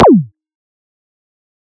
EDM Kick 44.wav